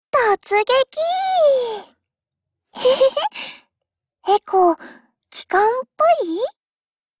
Cv-20129_warcry.mp3